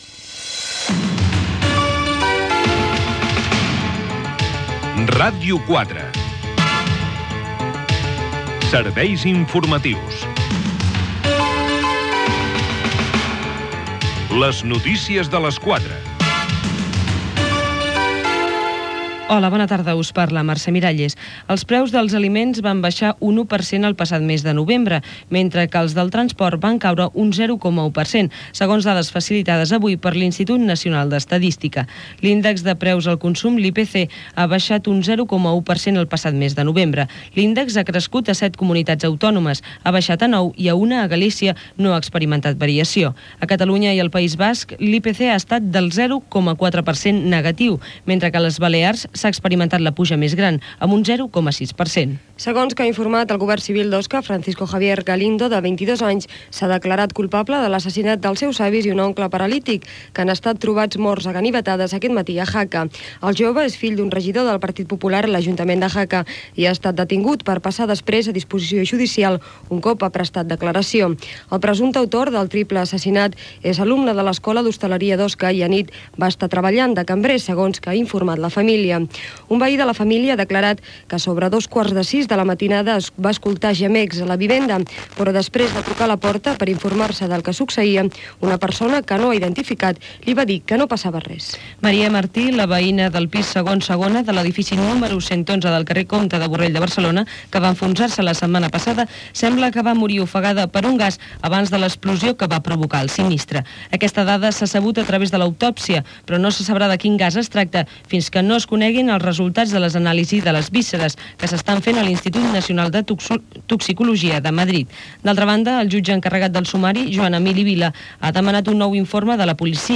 Informatiu
FM